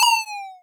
vvvvvv_hurt.wav